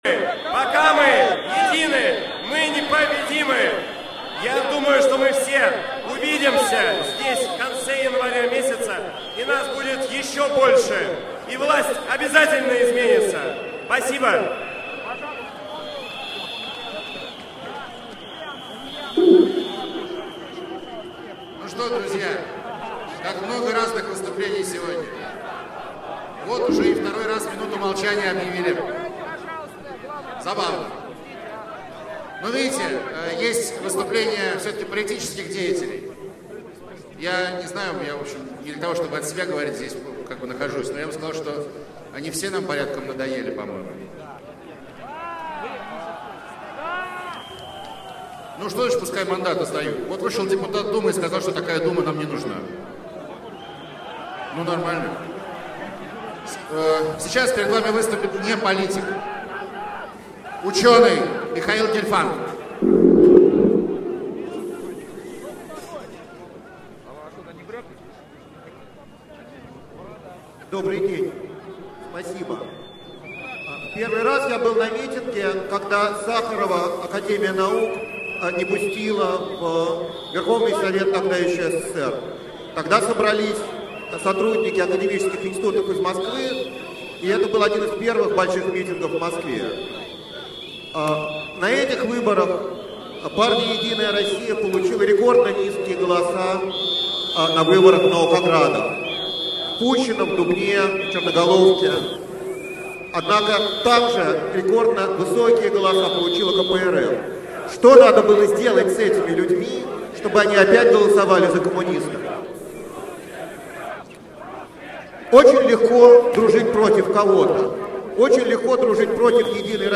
miting_protesta_na_bolotnoy_pl._10.12.2011._chast_1.mp3